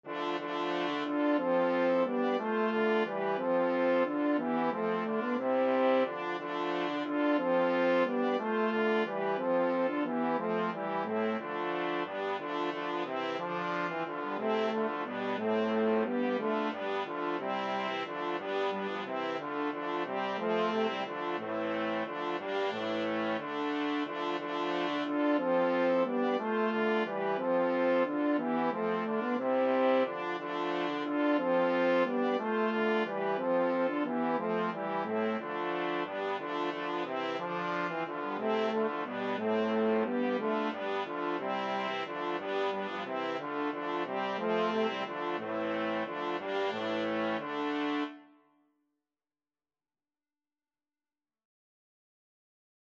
Free Sheet music for Brass Quartet
Trumpet 1Trumpet 2French HornTrombone
A minor (Sounding Pitch) (View more A minor Music for Brass Quartet )
6/4 (View more 6/4 Music)
Brass Quartet  (View more Easy Brass Quartet Music)
Classical (View more Classical Brass Quartet Music)
danserye_25_hoboeken_BRQT.mp3